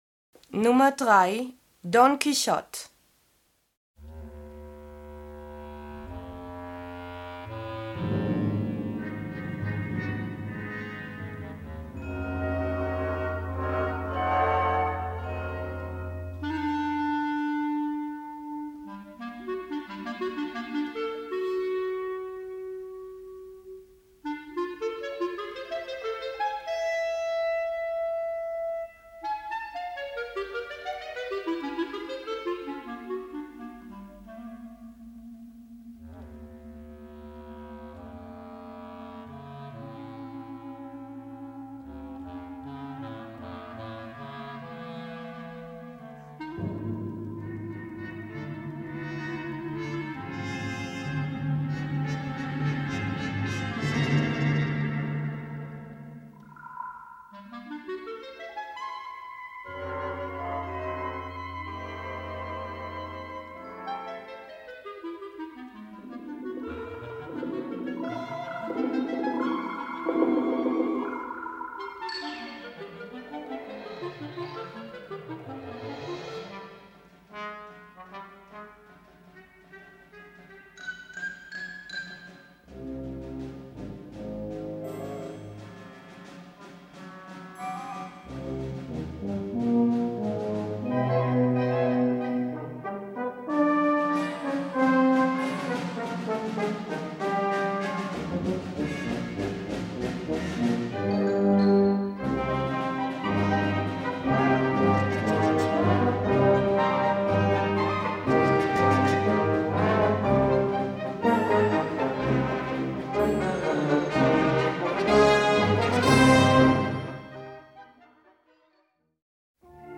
Gattung: Symphonische Suite in 5 Sätzen
Besetzung: Blasorchester